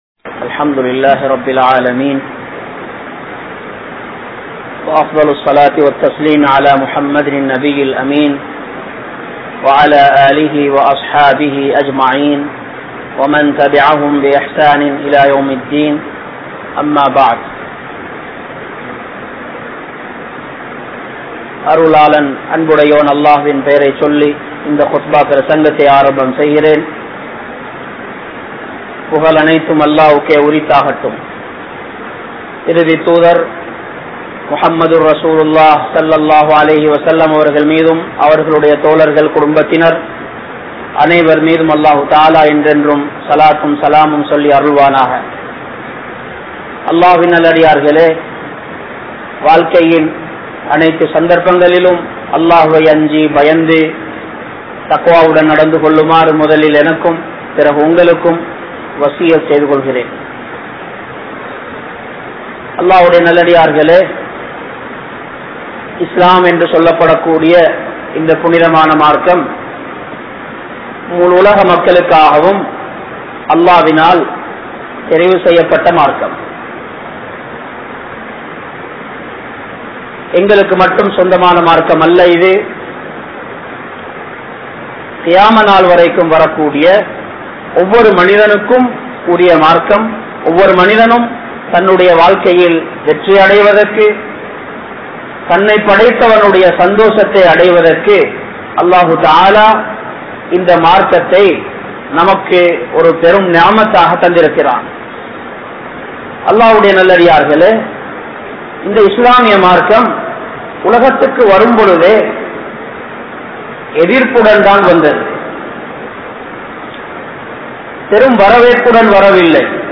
Soathanaikaana Koolihal | Audio Bayans | All Ceylon Muslim Youth Community | Addalaichenai
Junction Jumua Masjith